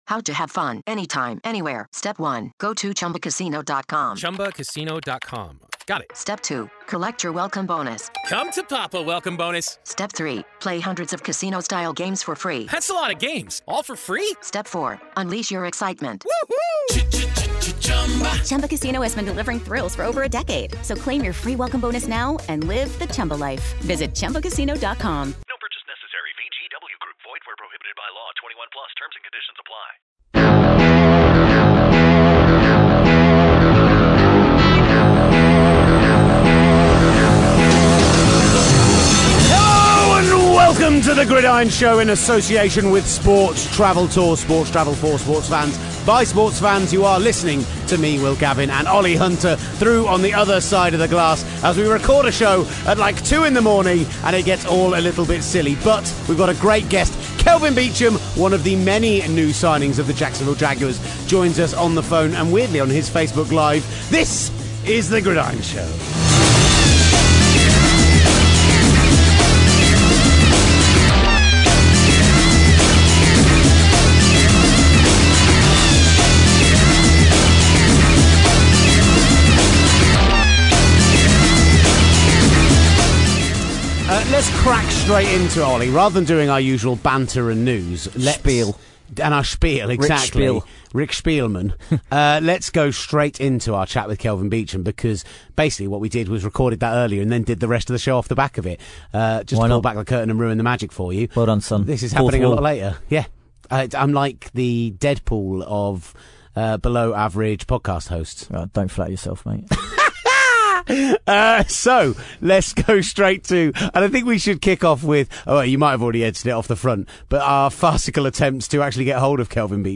One of the Jags many top level off season acquisitions and friend of the show Kelvin Beachum joins us on the phone!